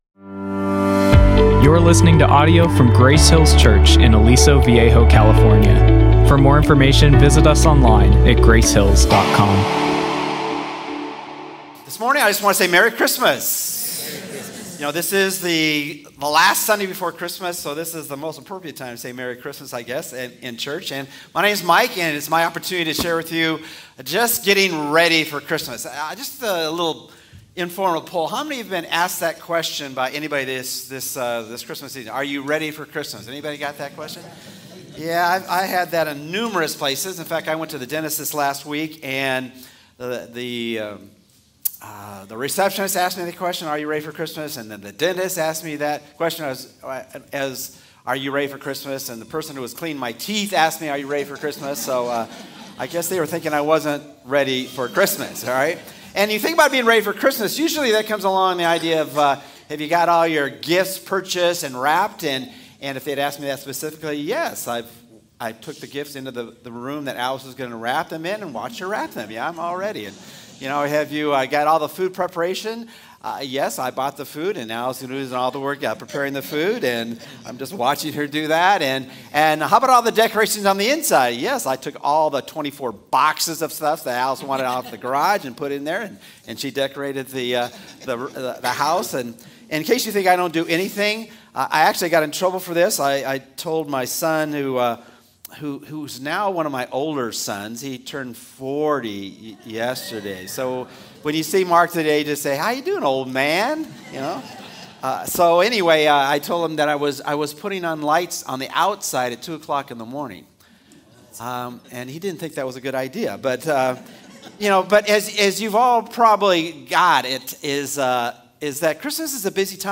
Grace Hills Church Sunday Sermons